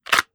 Kydex Unholster 002.wav